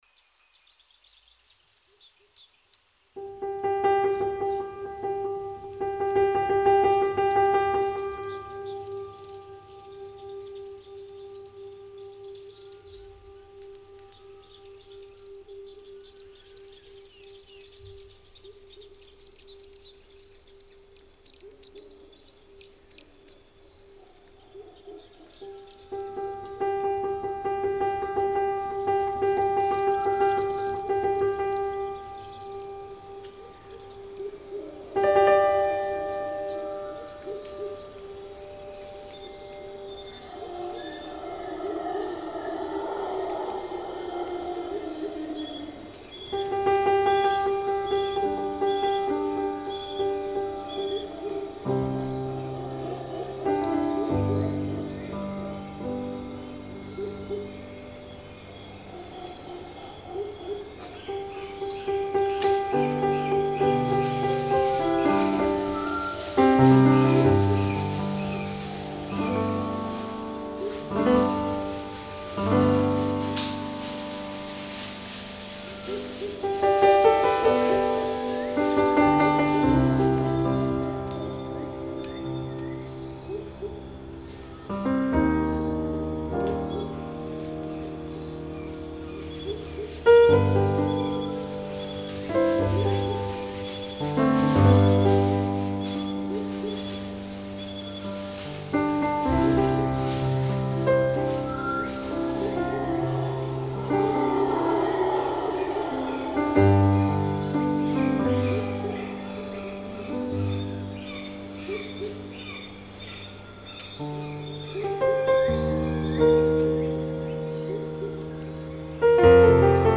Instrumental Jazz
Música popular: instrumental y jazz